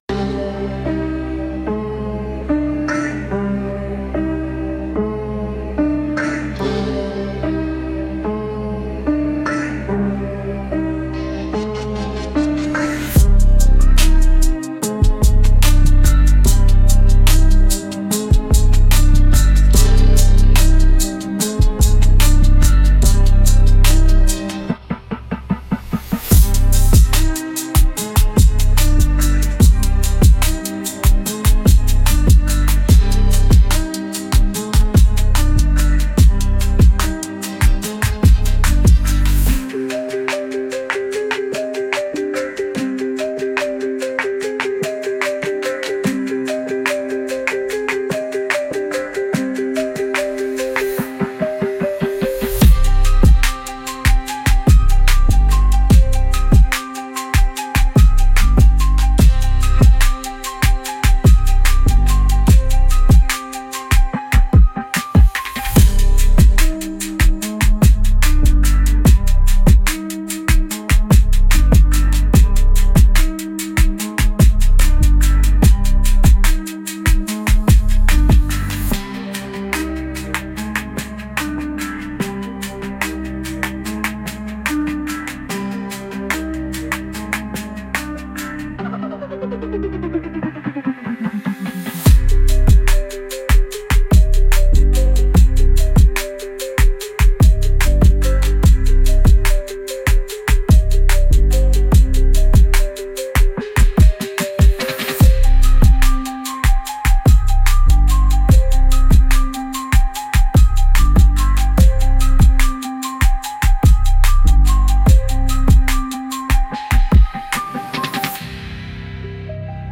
Instrumental - Real Liberty Media DOT xyz.